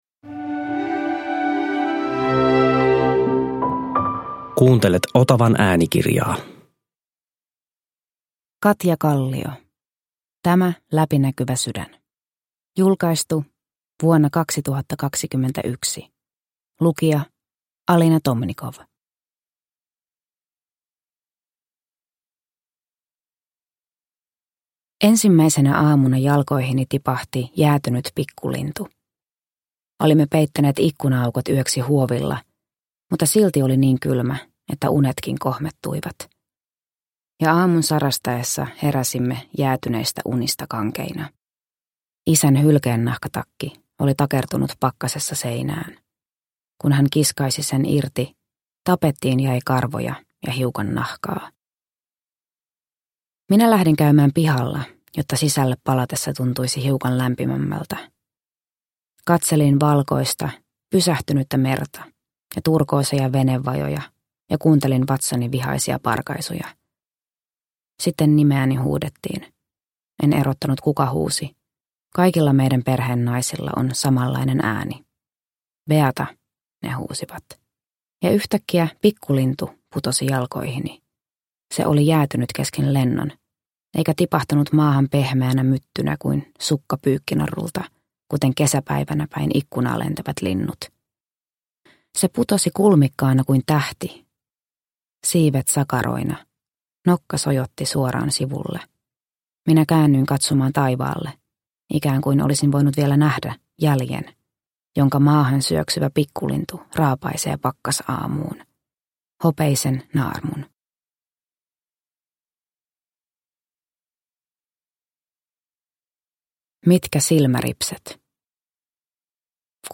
Tämä läpinäkyvä sydän – Ljudbok – Laddas ner